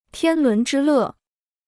天伦之乐 (tiān lún zhī lè): family love and joy; domestic bliss.